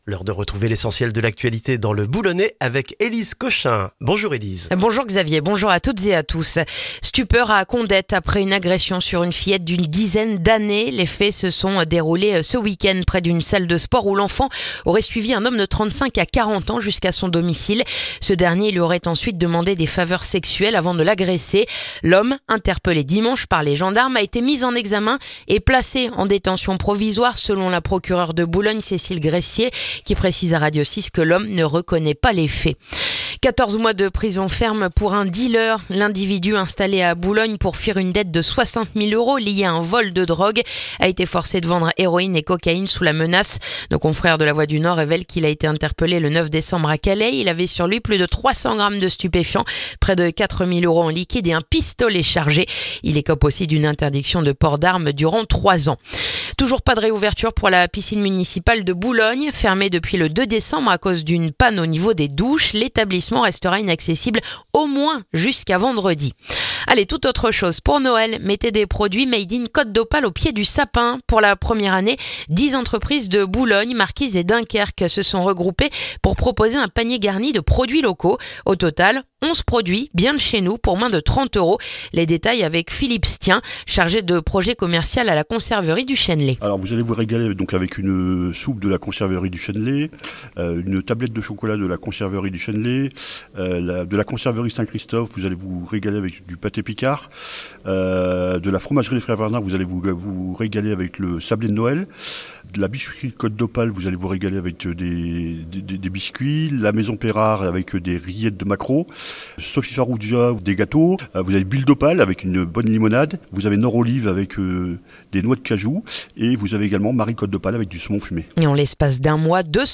Le journal du mercredi 17 décembre dans le boulonnais